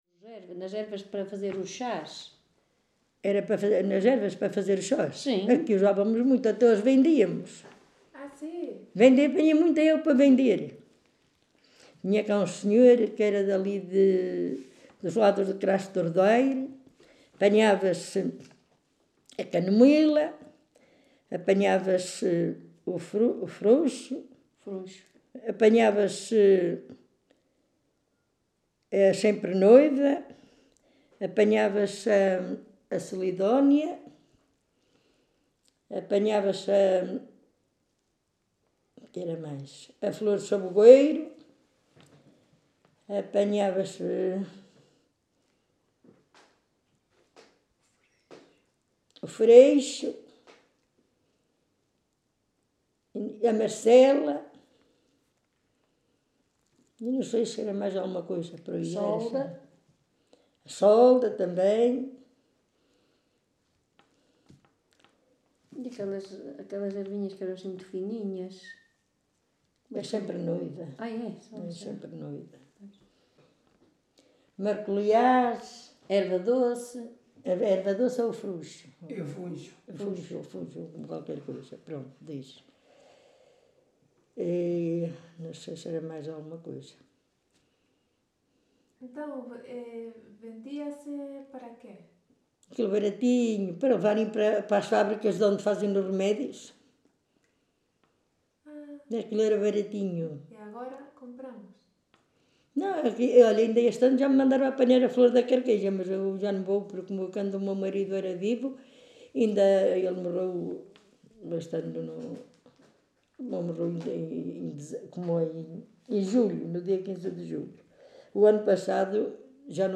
Várzea de Calde, primavera de 2019. Registo sonoro integrado numa série de gravações realizadas para o projeto Viseu Rural 2.0 e para o Arquivo da Memória de Várzea de Calde, cujo tema principal são diversos usos e conhecimentos sobre plantas: medicina, culinária, construção de ferramentas...
Tipo de Prática: Inquérito Oral